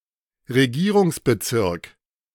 A Regierungsbezirk (German pronunciation: [ʁeˈɡiːʁʊŋsbəˌtsɪʁk]
De-Regierungsbezirk.ogg.mp3